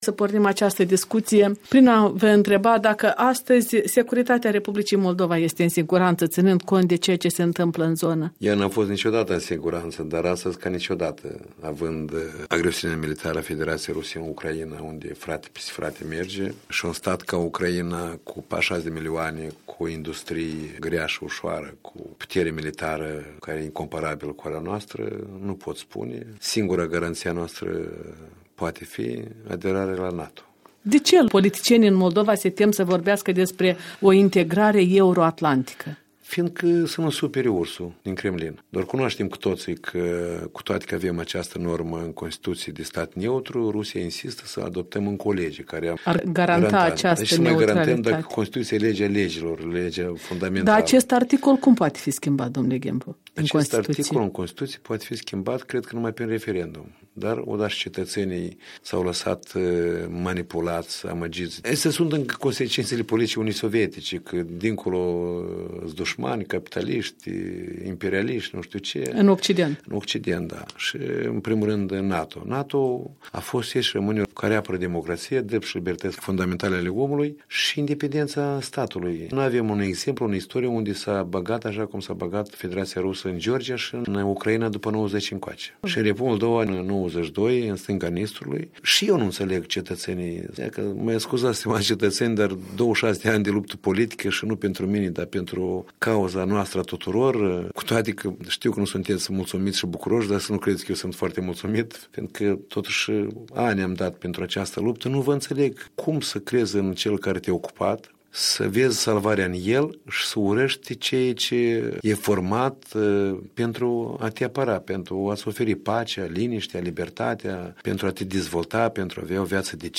Interviu cu președintele Partidului Liberal